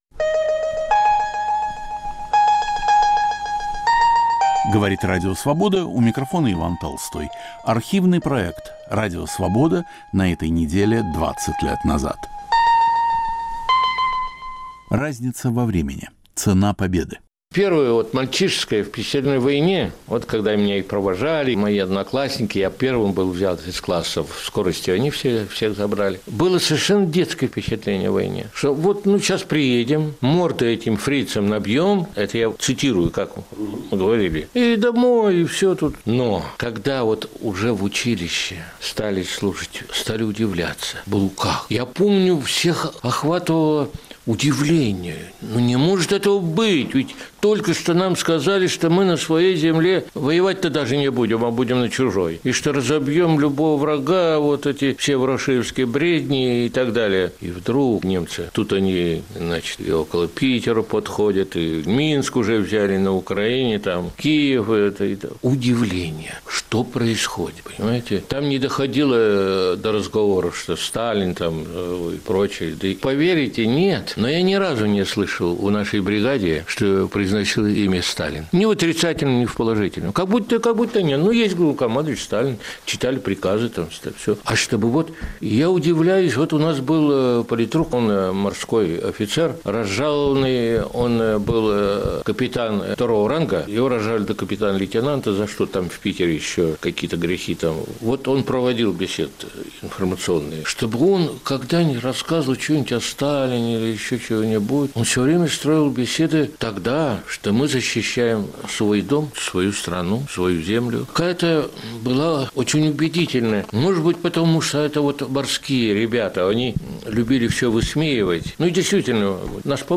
О своем отношении к войне говорит ее участник - инвалид, кавалер боевых орденов - академик Александр Николаевич Яковлев.